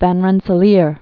(văn rĕnsə-lîr, rĕnsə-lər, vän rĕnsə-lär), Killian or Kiliaen 1595-1644.